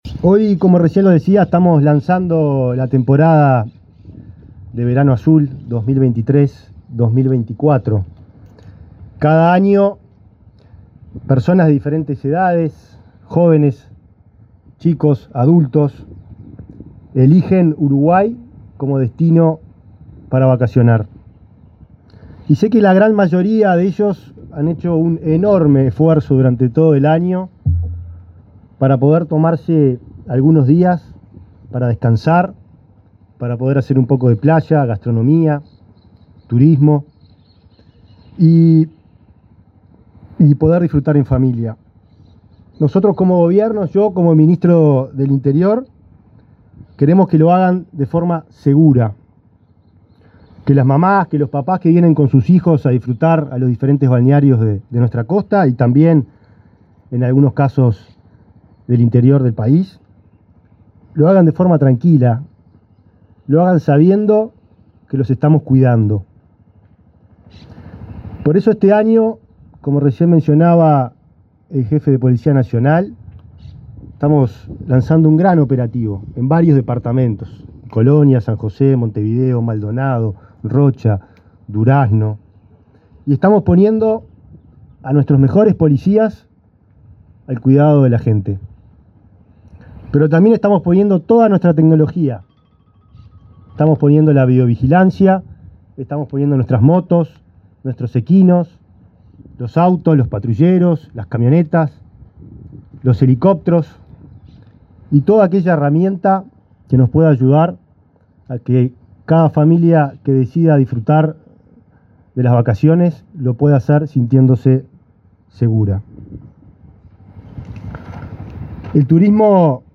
Palabras de autoridades en acto del Ministerio del Interior
Palabras de autoridades en acto del Ministerio del Interior 08/12/2023 Compartir Facebook X Copiar enlace WhatsApp LinkedIn El ministro del Interior, Nicolás Martinelli, y el director de la Policía Nacional, Juan Manuel Azambuya, participaron, este viernes 8 en el balneario Solís, Maldonado, en el lanzamiento del plan Verano Azul.